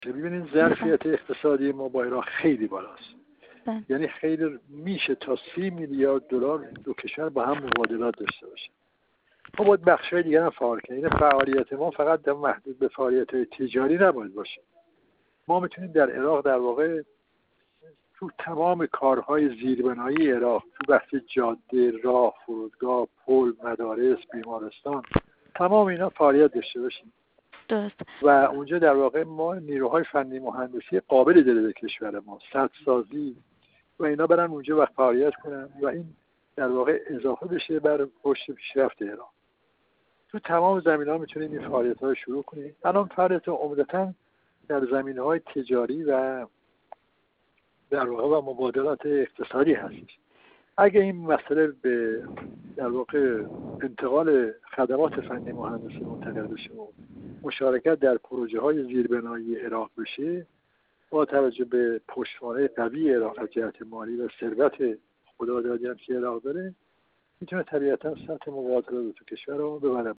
در این راستا سیدرضا میرابیان، سفیر اسبق جمهوری اسلامی ایران در کویت و کارشناس ارشد مسائل غرب آسیا، در گفت‌وگو با ایکنا در مورد نخستین سفر خارجی رئیس‌جمهور به کشور عراق، گفت: معمولاً اغلب یا برخی از رؤسای کشور سفر به عراق را به دلیل اهمیت روابط، نزدیکی، استراتژیک بودن روابط دو کشور و همسایگی دینی، مذهبی و انسانی همواره در اولویت‌های سفرهای خارجی خود قرار داده‌اند.